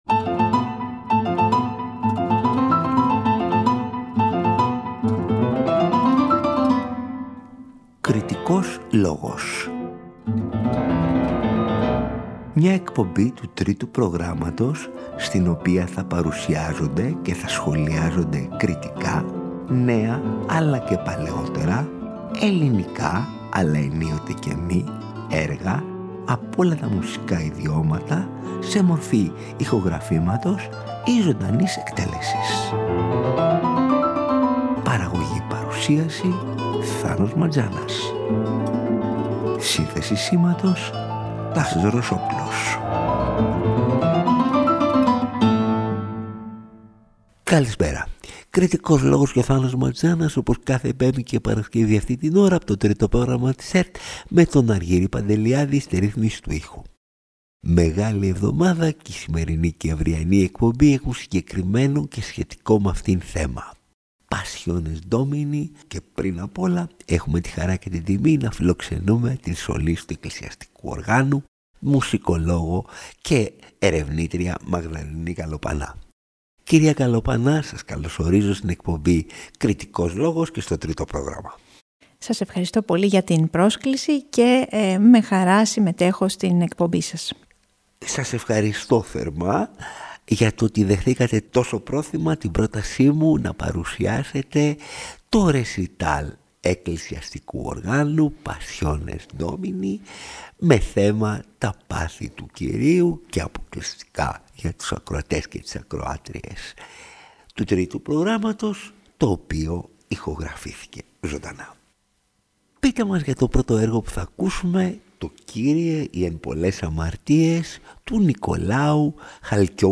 ρεσιτάλ εκκλησιαστικού οργάνου
Το ρεσιτάλ εκκλησιαστικού οργάνου ηχογραφήθηκε ζωντανά, χωρίς επιπλέον παρεμβάσεις στο στούντιο και αποκλειστικά για τους ακροατές και τις ακροάτριες του Τρίτου Προγράμματος.
Όλα με το μαγευτικό, ατμοσφαιρικό και υποβλητικό εύρος ηχοχρωμάτων του εκκλησιαστικού οργάνου που, ανεξάρτητα από την δυτική προέλευση του, προσδίδει μια ιδιαίτερη κατανυκτική διάθεση στην υμνολογία των Παθών Του Κυρίου.